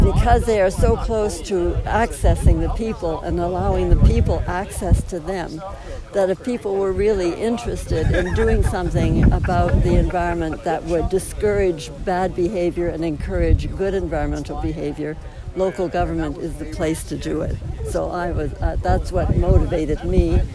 During her remarks on Monday, Langer stressed the important role that local government can play in keeping environmentalism top of mind for residents.